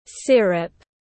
Si-rô tiếng anh gọi là syrup, phiên âm tiếng anh đọc là /ˈsɪr.əp/
Syrup /ˈsɪr.əp/